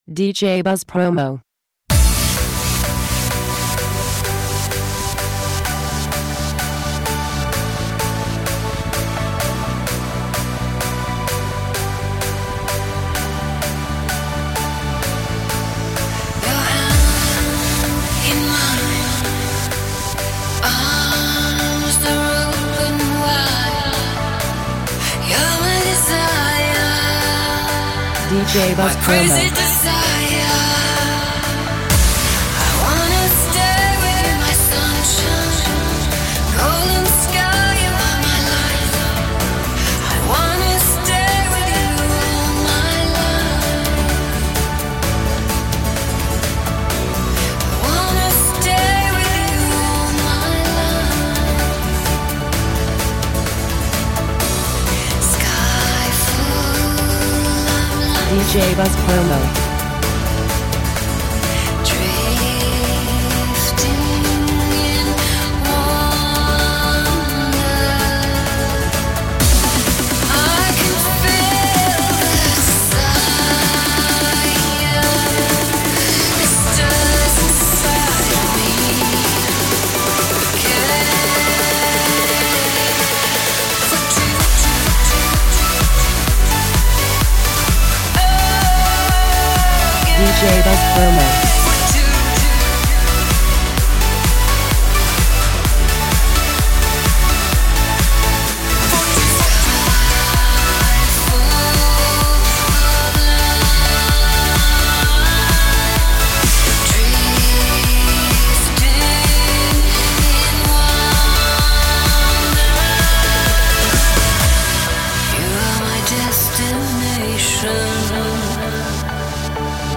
The powerfully infectious single
this playful tune